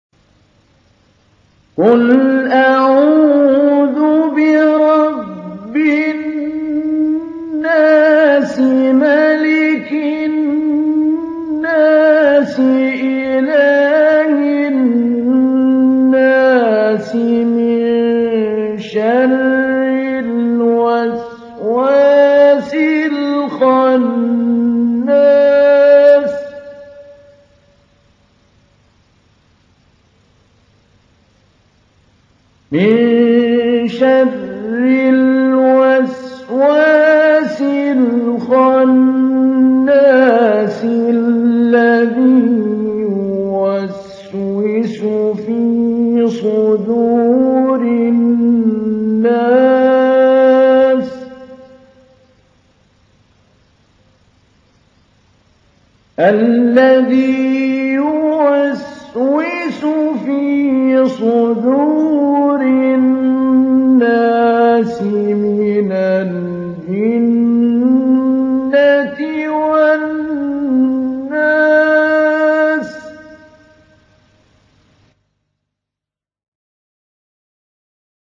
تحميل : 114. سورة الناس / القارئ محمود علي البنا / القرآن الكريم / موقع يا حسين